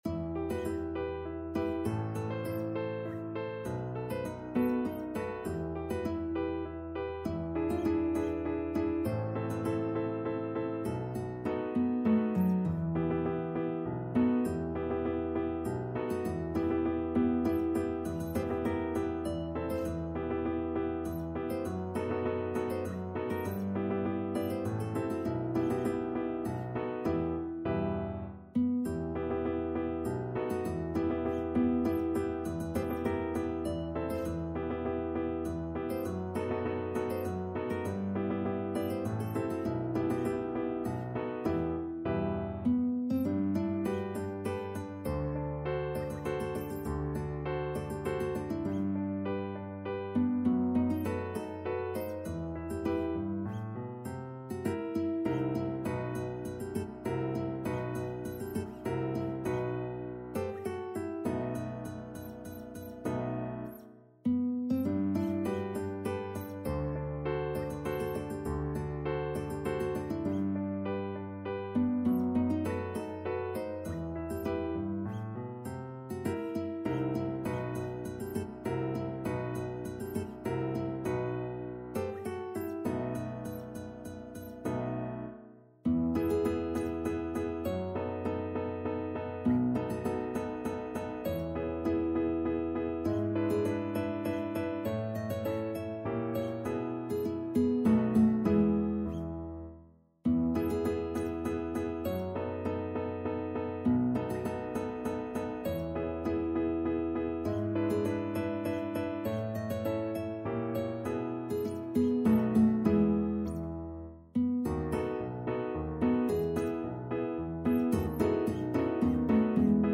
Classical (View more Classical Guitar Music)